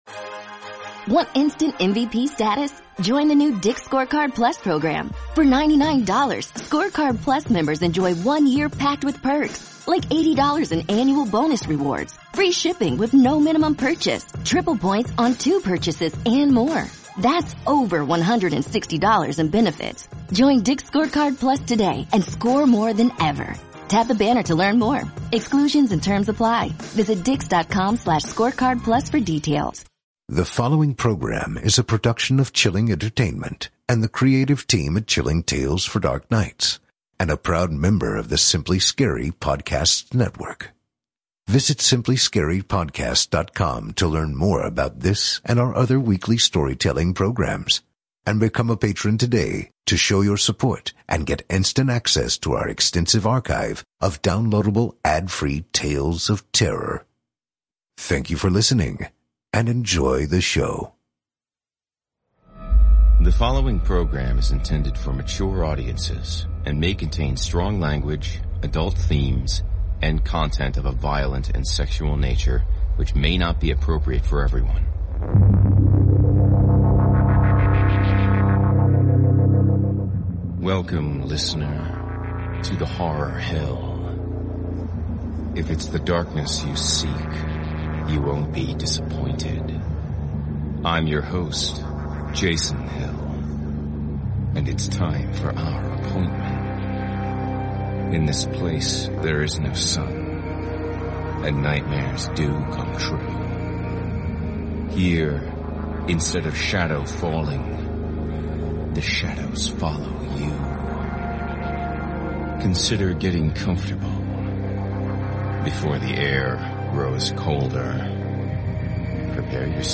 A Horror Fiction Anthology and Scary Stories Series Podcast